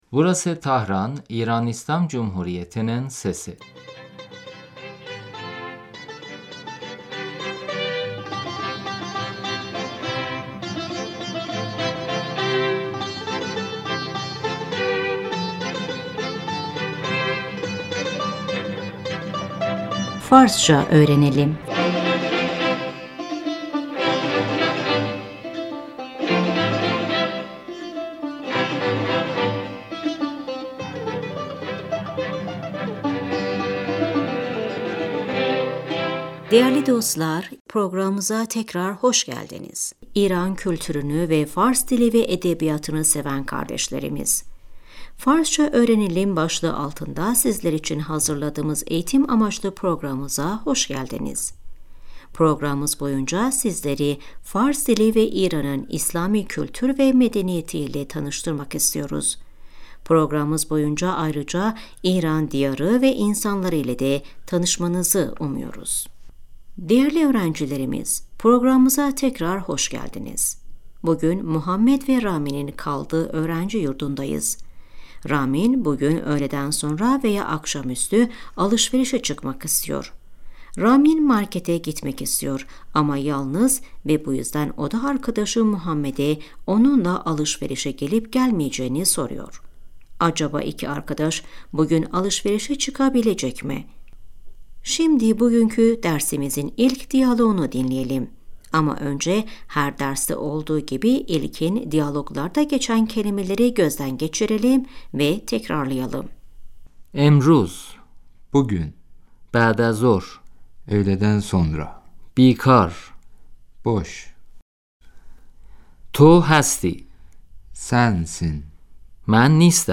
در اتاق Odada, kağıt sesi رامین - محمد ، تو امروز بعد از ظهر بیکاری ؟